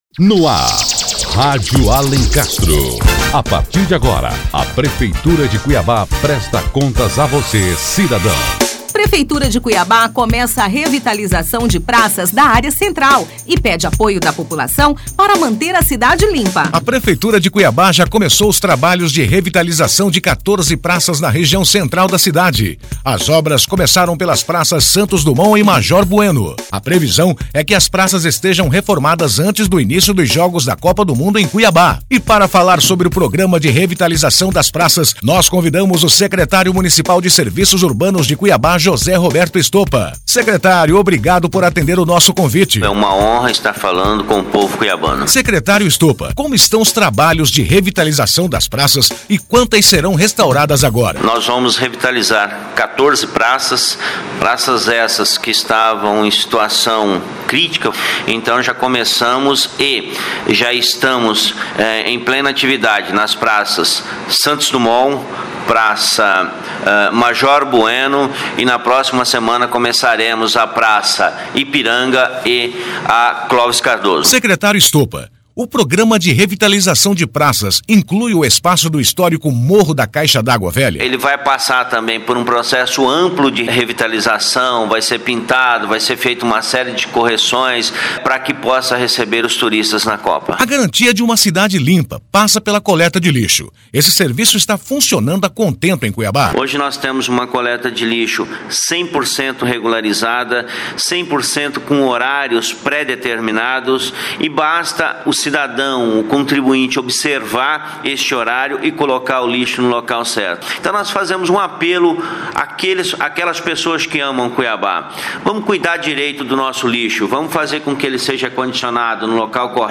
José Roberto Stopa, Secretário Municipal de Serviços Urbanos, fala mais sobre este programa de revitalização.